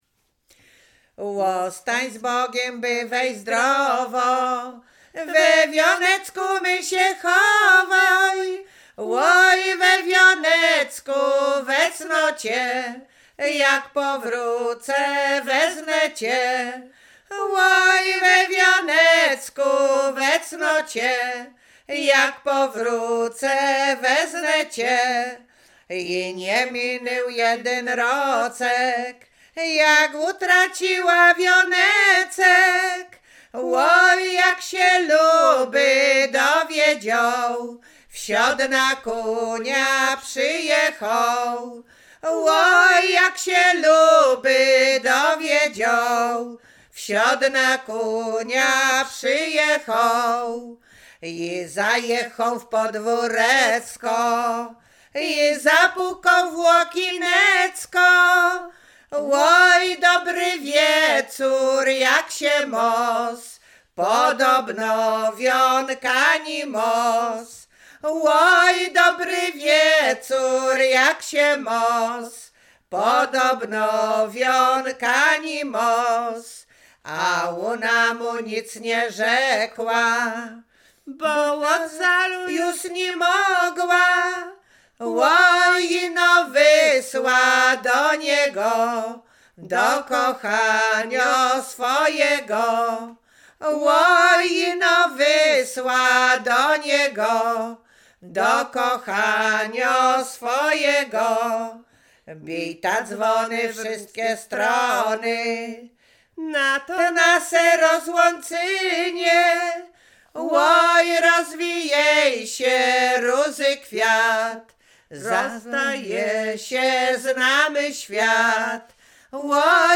Łowickie
miłosne liryczne rekruckie